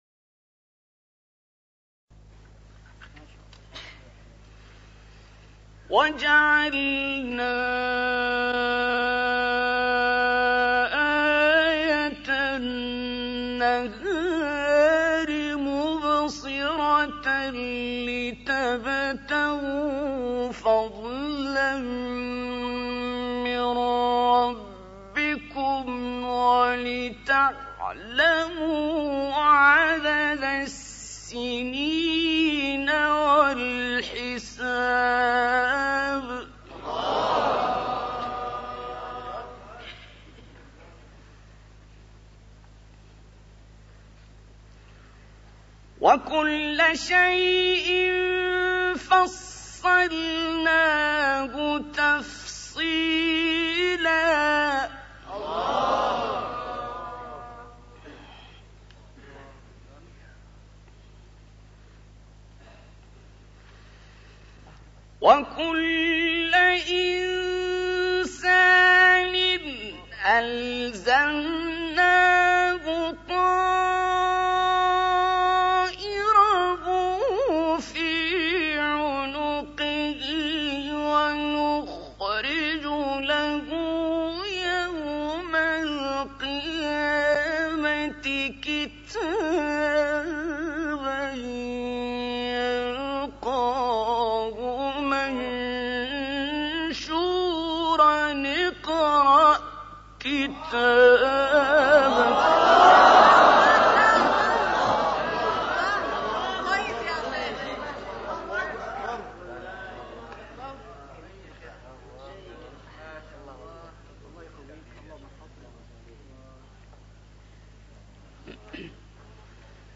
3 تلاوت «عبدالباسط» در آفریقای جنوبی
گروه شبکه اجتماعی: تلاوت‌های متفاوتی از تلاوت عبدالباسط محمد عبدالصمد که در سال 1981 میلادی در آفریقای جنوبی اجرا شده است، می‌شنوید.
تلاوت اول